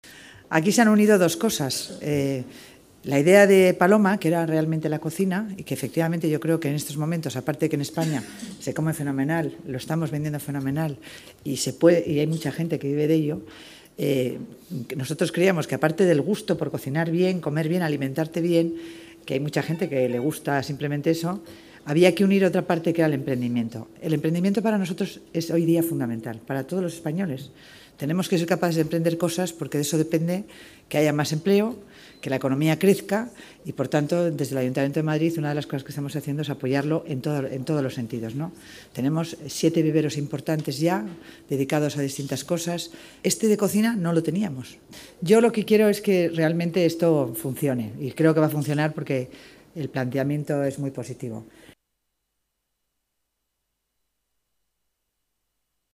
Nueva ventana:Declaraciones delegada Economía y Hacienda, Concepción Dancausa: La Kitchen Tetuán